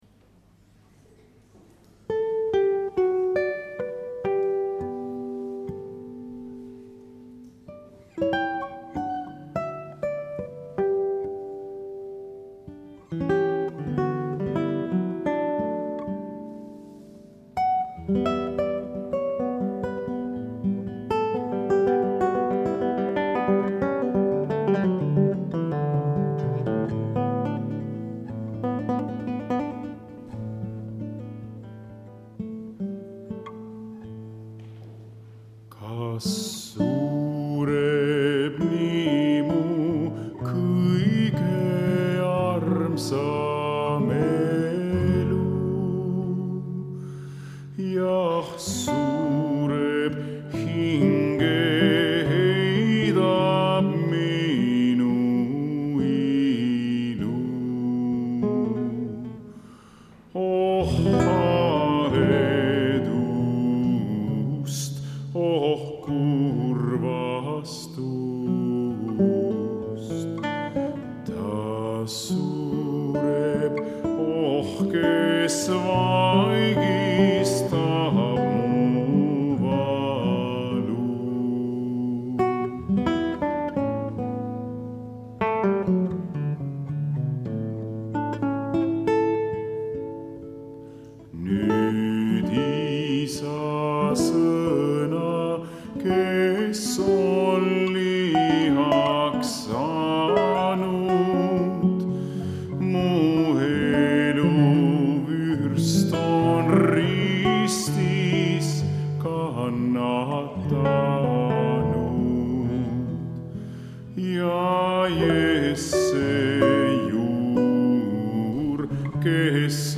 Pühakirja lugemine ja palve
Jutlus